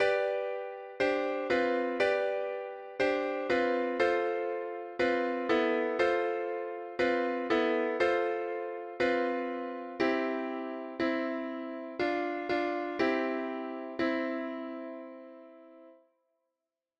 This is a song I made with MuseScore while I was trying to do stuff for something else.
pianosong.ogg